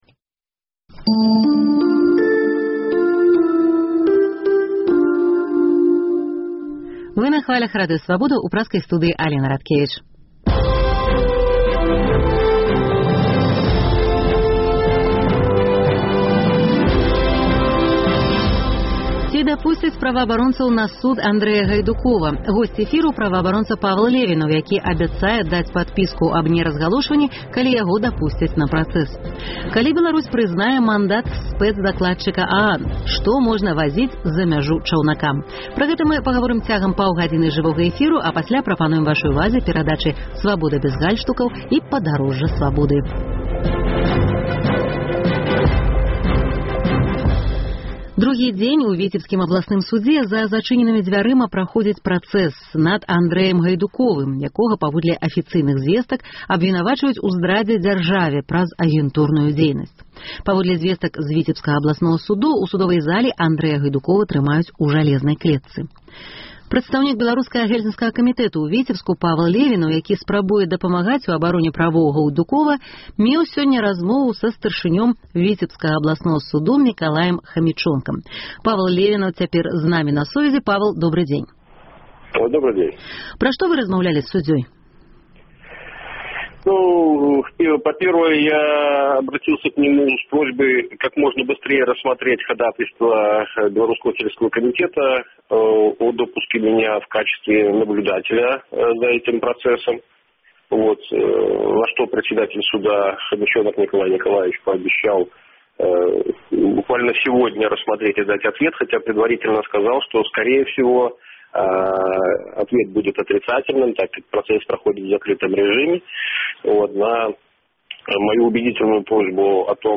Госьць эфіру – праваабаронца